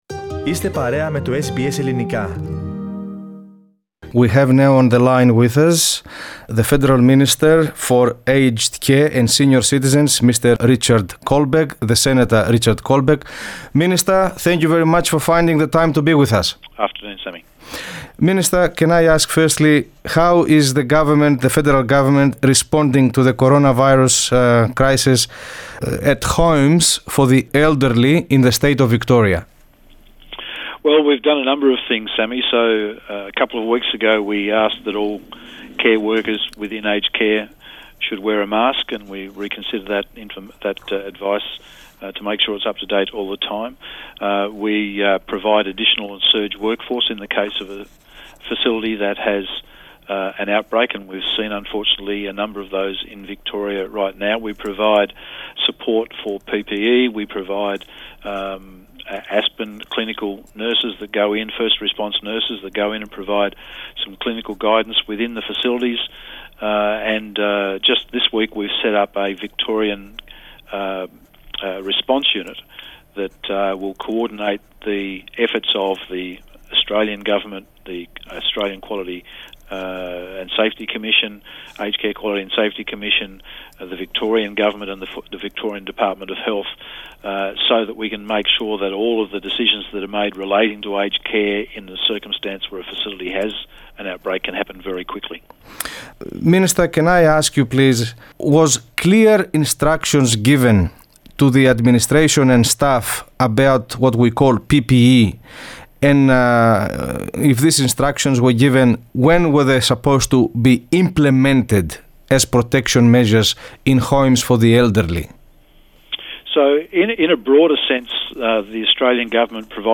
Τούτο δήλωσε, μεταξύ άλλων, ο υπουργός Φροντίδας Ηλικιωμένων, Ρίτσαρντ Κόλμπεκ, σε συνέντευξη που παραχώρησε στο Ελληνικό Πρόγραμμα